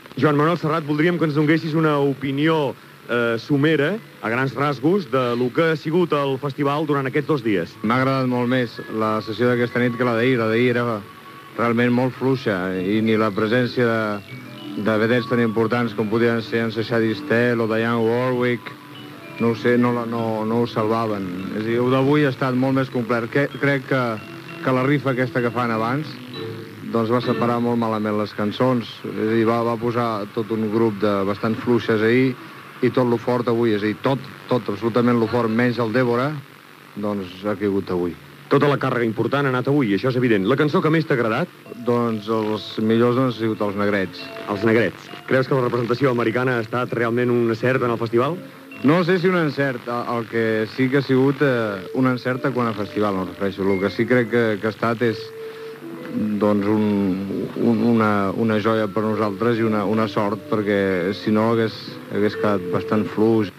Valoració del cantnat Joan Manuel Serrat dels dos primers dies del "Festival della canzone italiana", celebrat a San Remo
Fragment extret del programa "La ràdio que vam sentir", de Ràdio Barcelona, emès el 18 de novembre de 2001.